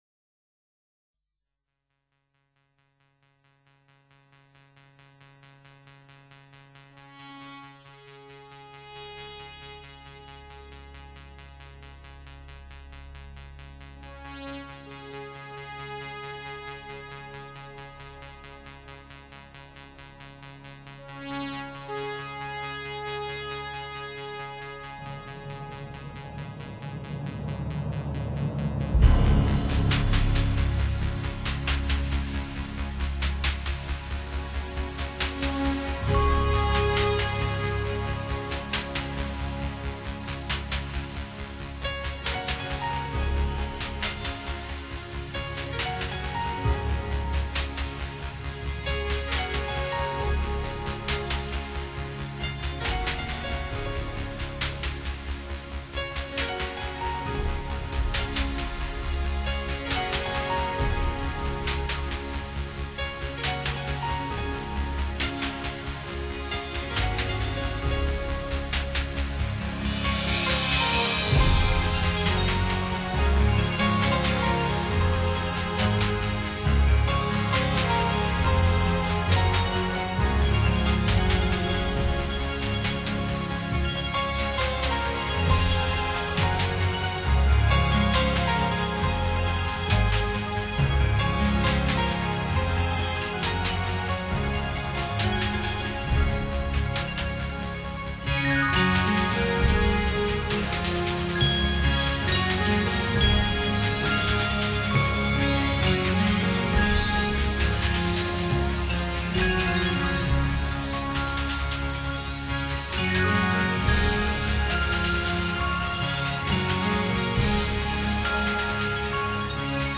Genre: New Age, Electronic
衬上竖琴演奏与些许电子合成器声效